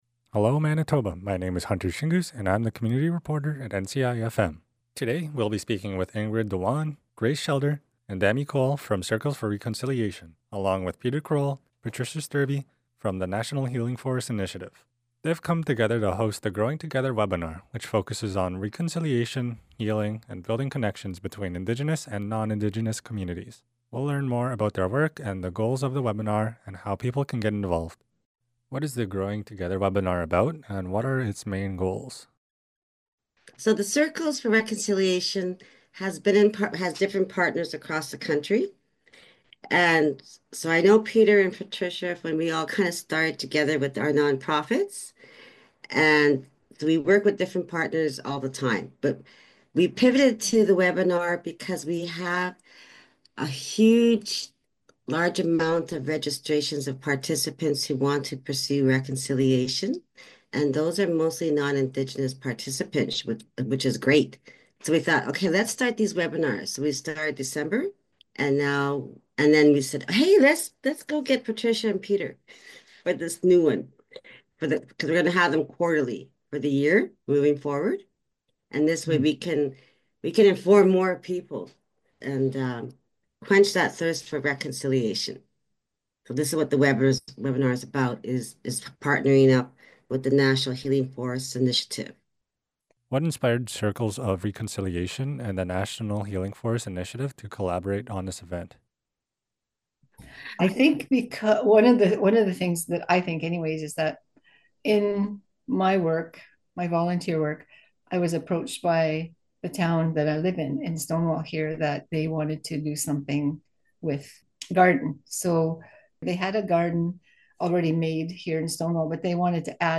NCI-FM Radio Interview